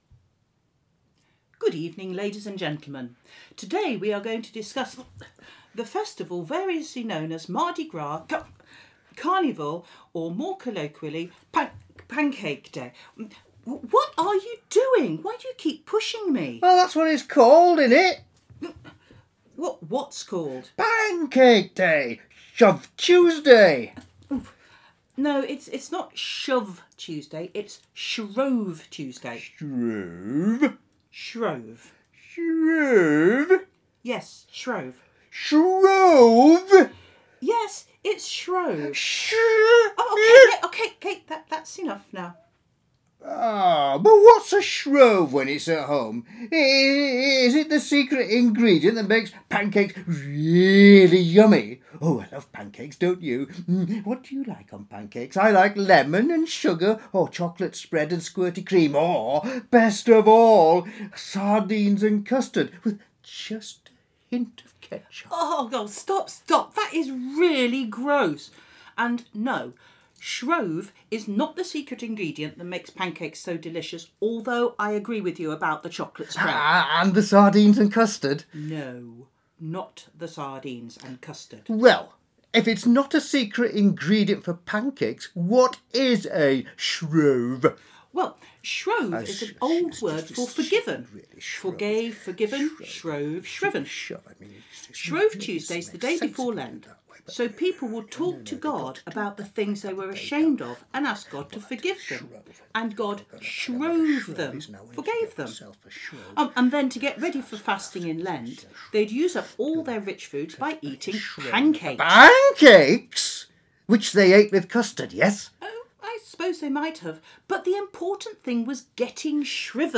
All is revealed in this amusing sketch with a clear gospel message at the end.